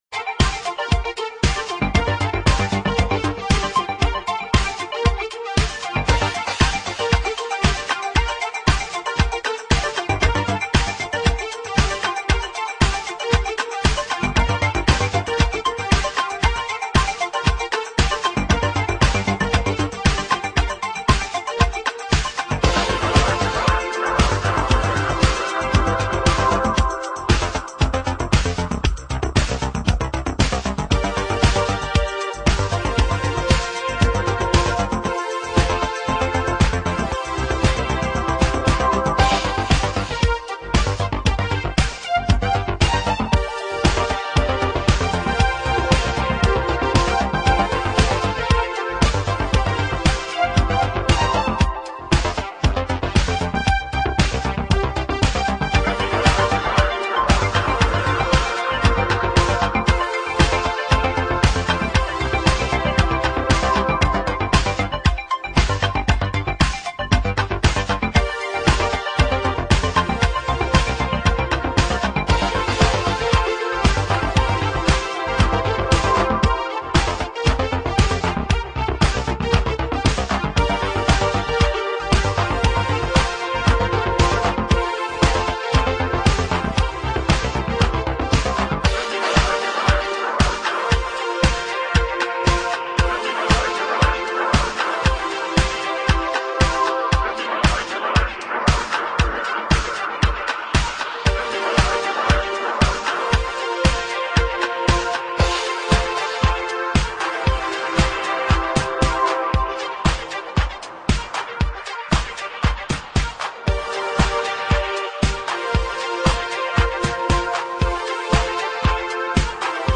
レトロ・フューチャーなサウンドが最高です！